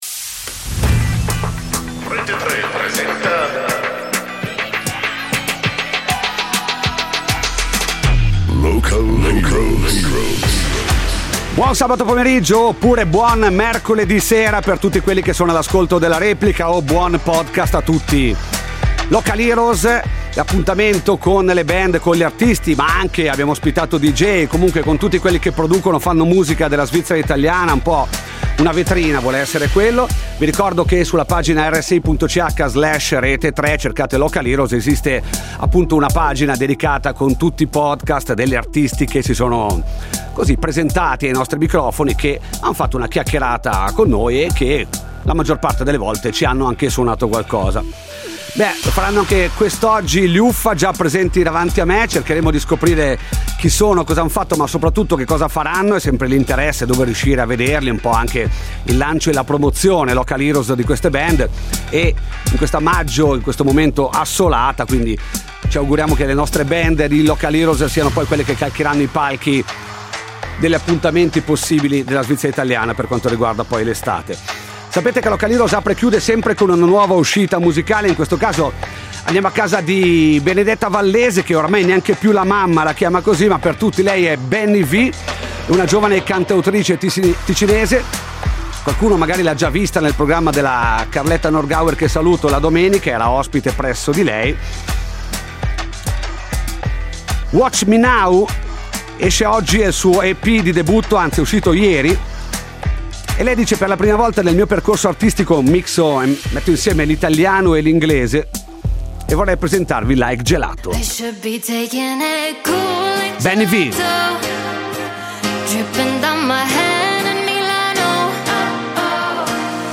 Saranno gli UFFA a raccontarsi e a cantare live in questa puntata di Local Heroes